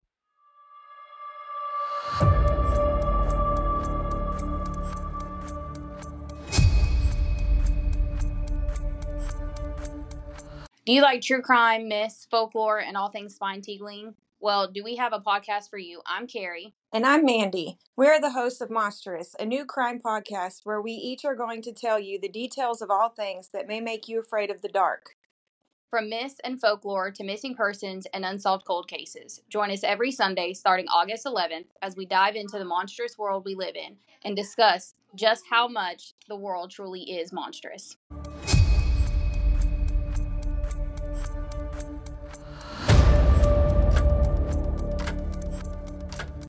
Monstrous is a true crime podcast with a dash of all things spooky, mystical and creepy hosted by two southern girls living in the Midwest with a love of all things spine tingling. Join us every Sunday as we cover murders, serial killers, cold cases, mysteries, cults and all things monstrous.